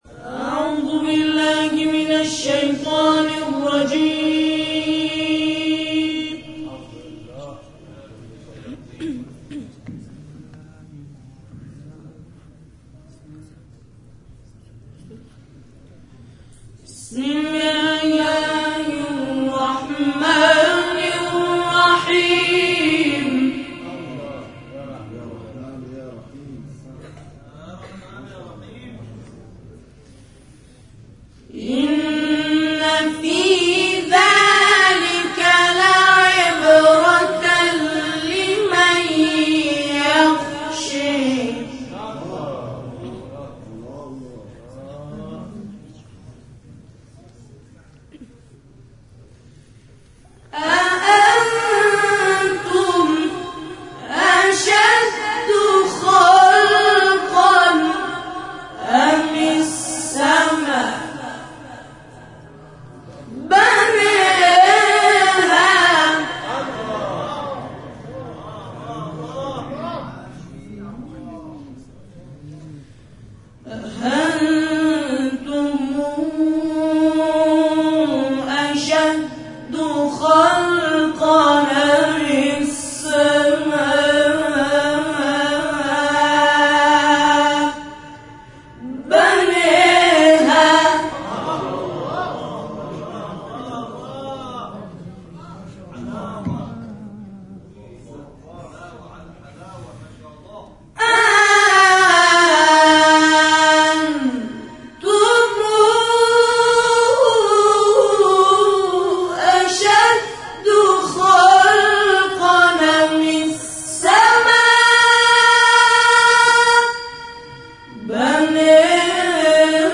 به گزارش خبرگزاری بین‌المللی قرآن(ایکنا)، روز گذشته، 22 فروردین‌ماه همزمان با سالروز میلاد حضرت علی(ع) مراسم باشکوه جشنی به همین منظور به همت مؤسسه احسن‌الحدیث واقع در میدان امام حسین(ع)، خیابان ۱۷ شهریور، خیابان صفای شرقی با حضور اساتید و قاریان ممتاز و بین‌المللی برگزار شد.
در خلال تلاوت‌های این برنامه، گروه تواشیح سبیل‌الرشاد، به جمع‌خوانی آیاتی از سوره مبارکه نازعات و شمس به سبک استاد مصطفی اسماعیل پرداختند و مورد تشویق حاضران در جلسه قرار گرفتند.